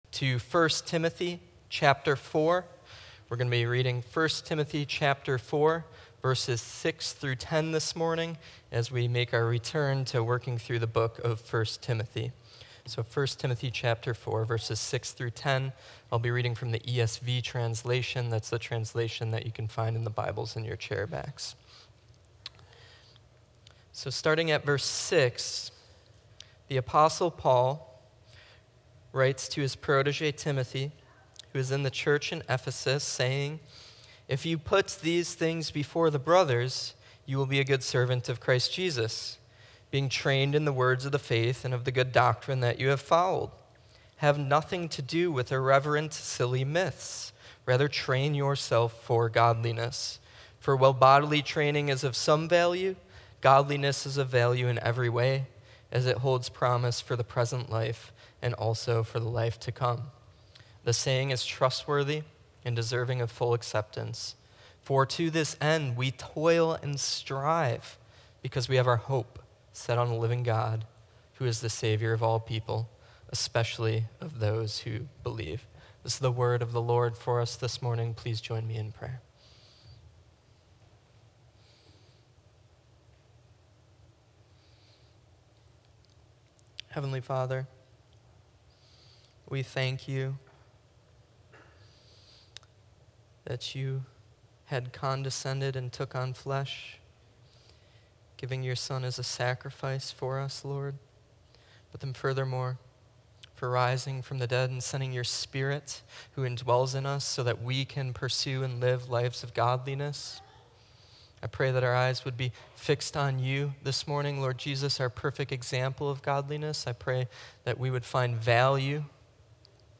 A sermon on 1 Timothy 4:6–10 emphasizing the importance of godliness, obedience to God’s Word, and hope in the living God through Christ Jesus.